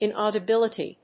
音節in・au・di・bil・i・ty 発音記号読み方/ìnɔːdəbíləṭi/ 名詞
音節in･au･di･bil･i･ty発音記号・読み方ɪnɔ̀ːdəbɪ́ləti